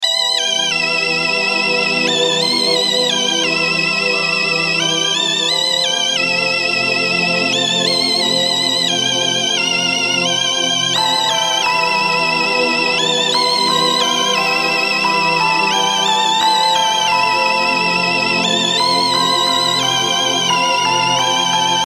描述：婴儿玩具音乐盒
玩具 摇篮曲
声道立体声